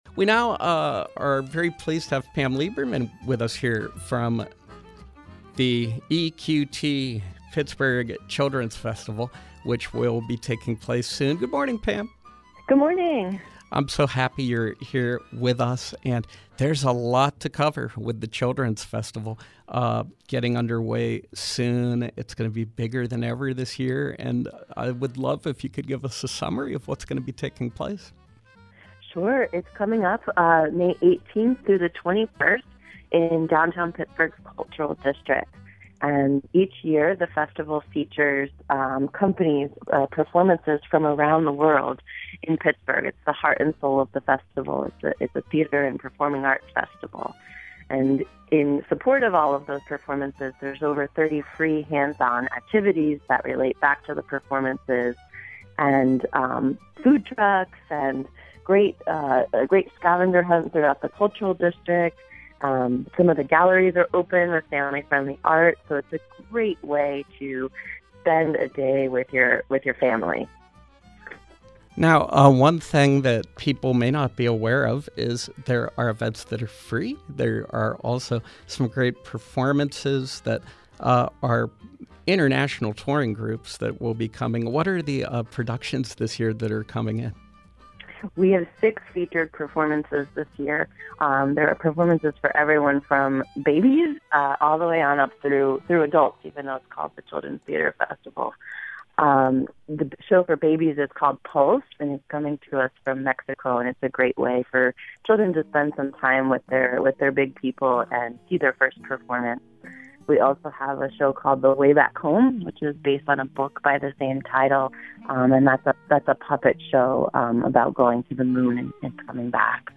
Interview: EQT Children’s Theater Festival 2017